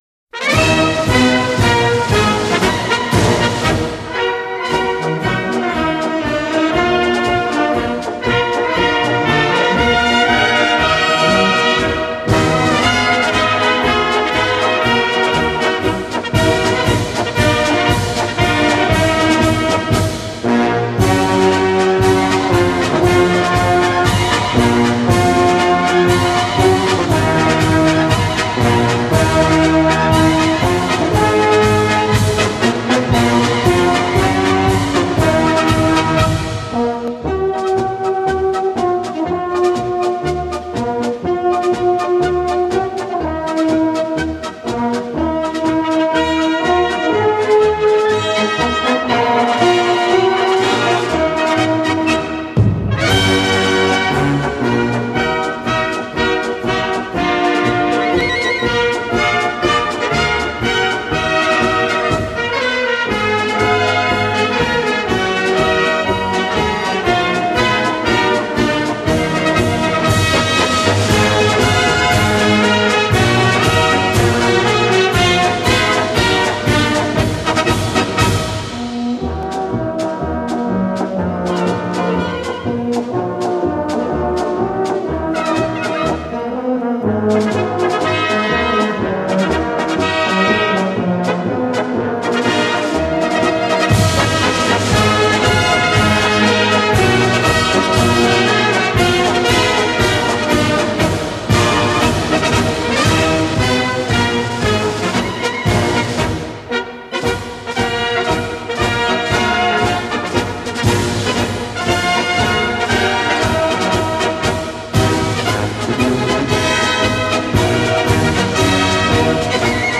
Марши
Описание: Небольшое повышение качества марша.